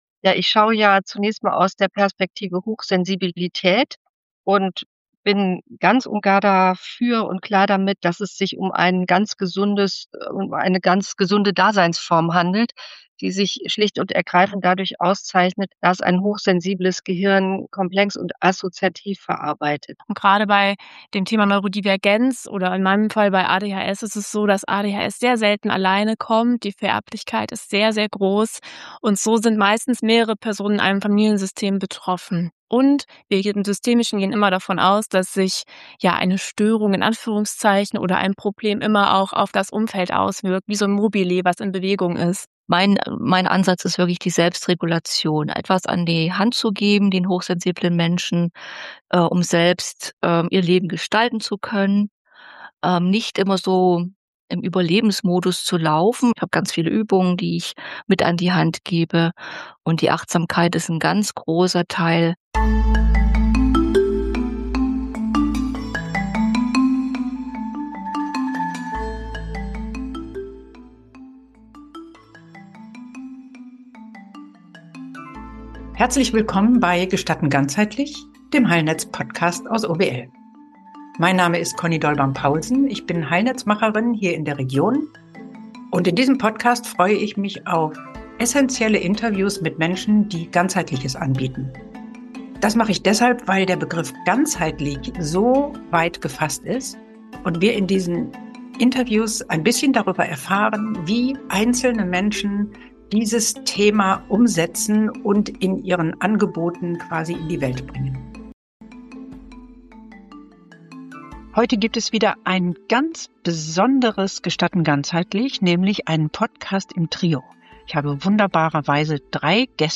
Hier hörst Du Interviews von Menschen, die im Heilnetz ganzheitliche Angebote machen und die im Podcast darüber sprechen, was ihnen dabei besonders wichtig...